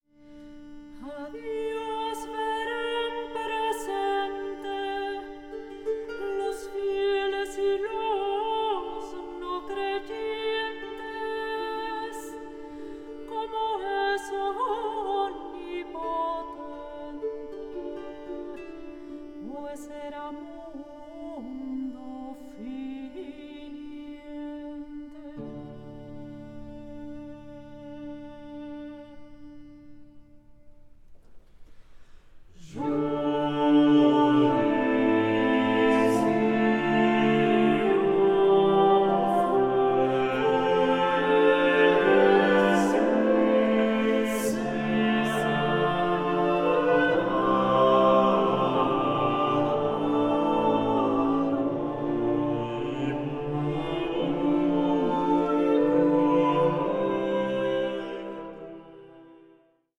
MELANCHOLIC SOLACE FOR TROUBLED TIMES